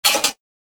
دانلود صدای موس 19 از ساعد نیوز با لینک مستقیم و کیفیت بالا
جلوه های صوتی
برچسب: دانلود آهنگ های افکت صوتی اشیاء دانلود آلبوم صدای کلیک موس از افکت صوتی اشیاء